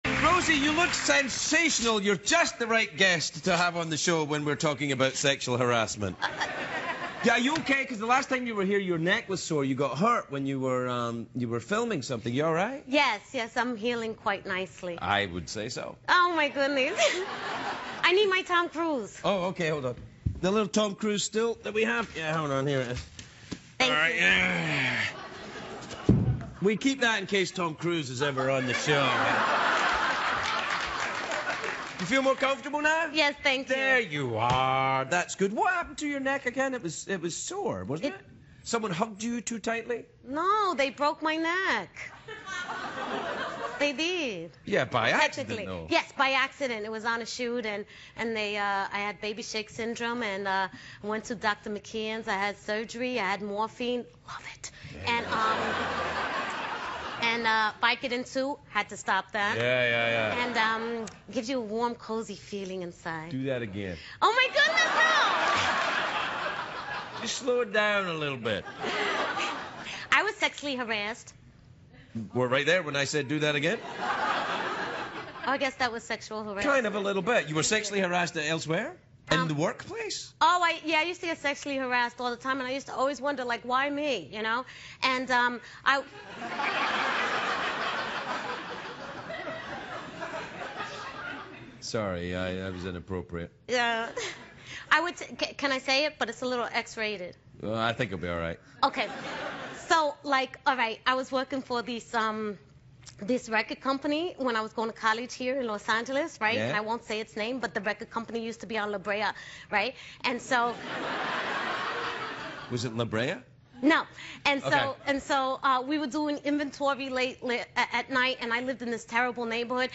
访谈录 2011-07-12&07-14 罗西·培瑞兹专访 听力文件下载—在线英语听力室